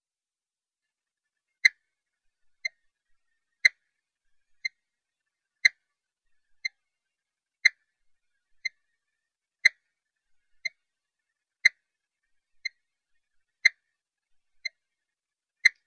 elektrische Feld detektiert werden - hörbar im Sekundentakt.